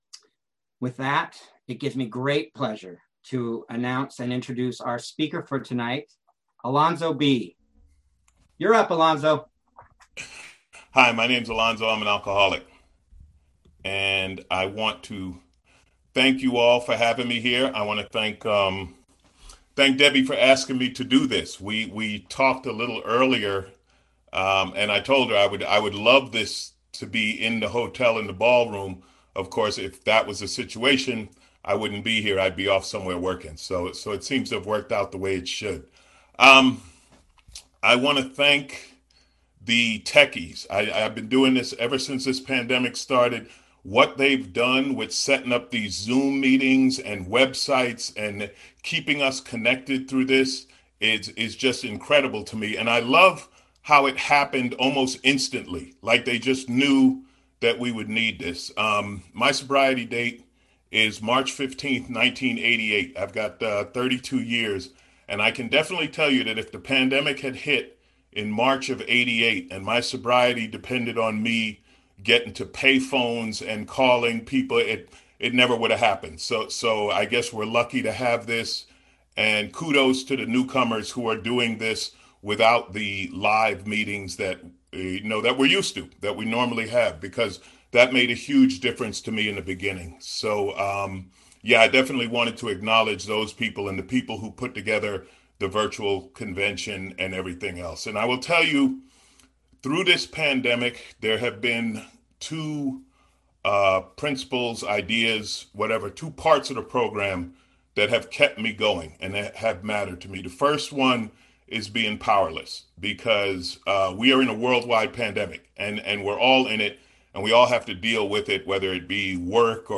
46th San Fernando Valley Alcoholics Anonymous UnConventional